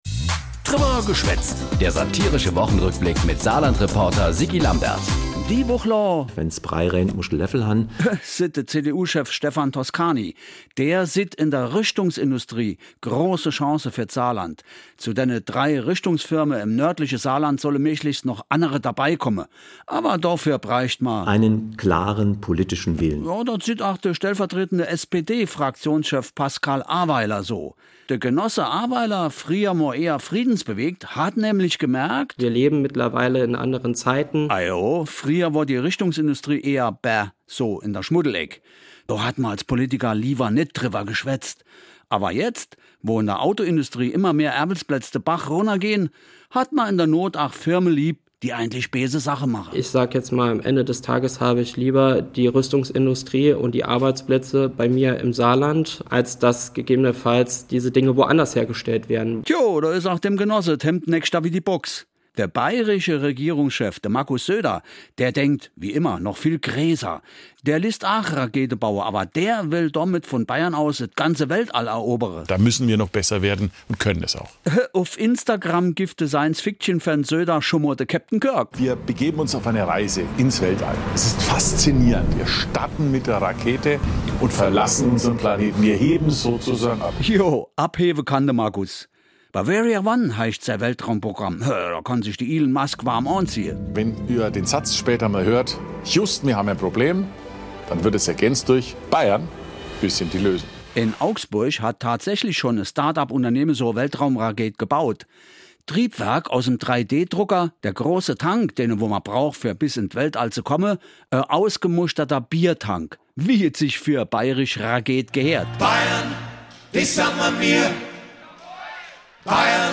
… continue reading 192 حلقات # Nachrichten # Der Satirische Wochenrückblick